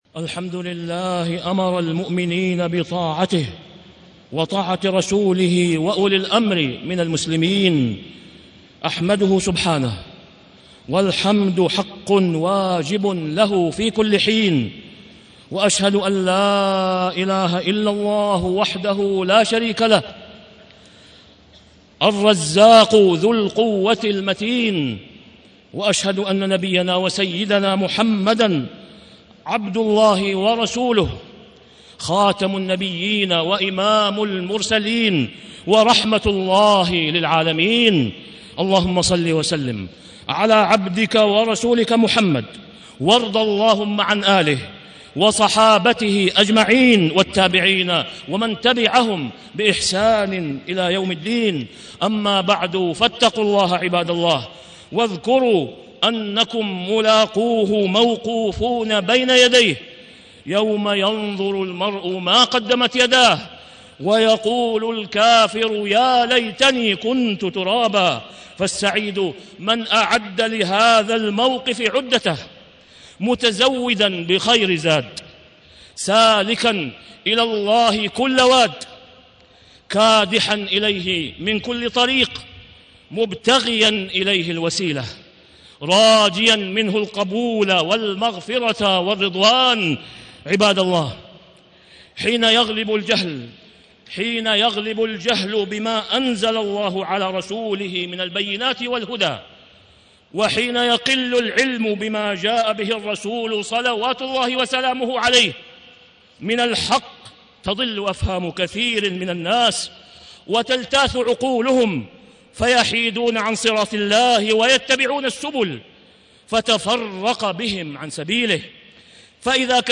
تاريخ النشر ٢٨ محرم ١٤٣٦ هـ المكان: المسجد الحرام الشيخ: فضيلة الشيخ د. أسامة بن عبدالله خياط فضيلة الشيخ د. أسامة بن عبدالله خياط حقوق الراعي والرعية